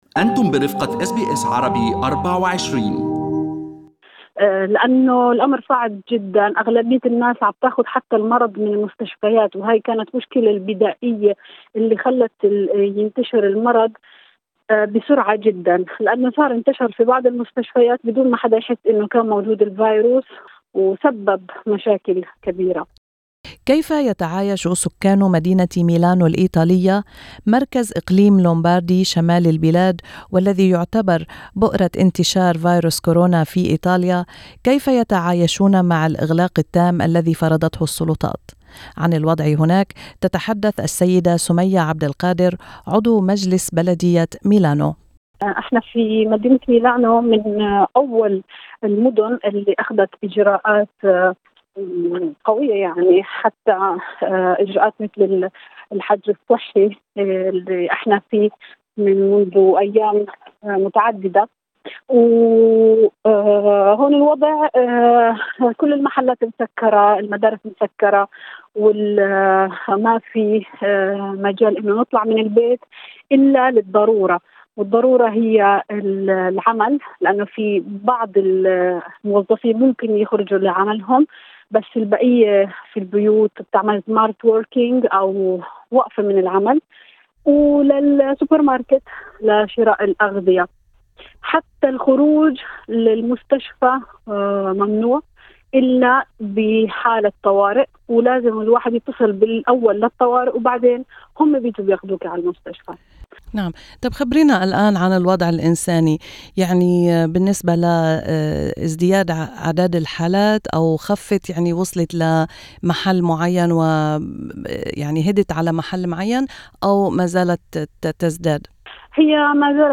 SKIP ADVERTISEMENT وفي لقاء مع برنامج أستراليا اليوم في SBS Arabic24 قالت السيدة سمية عبد القادر إن المرض مازال ينتشر بقوة، وأصبحت غرف العناية المركزة مشغولة باستمرار.